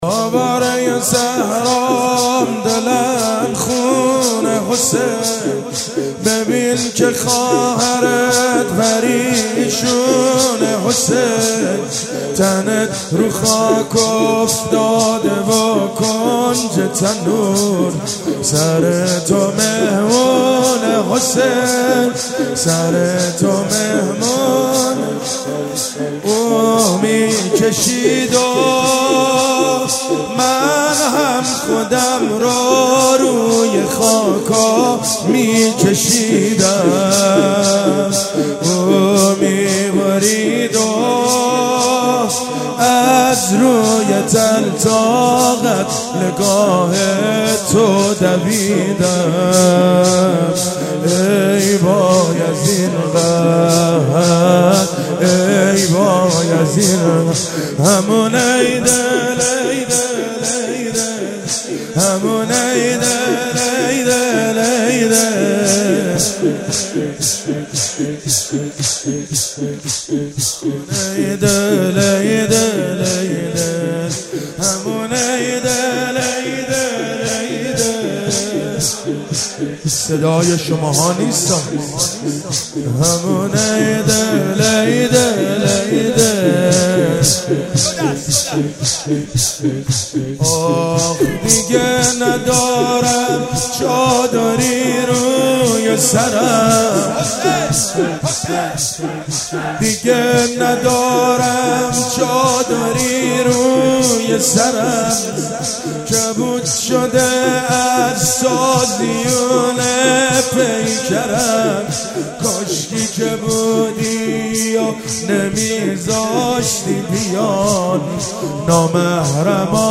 مناسبت : دهه دوم محرم
قالب : زمینه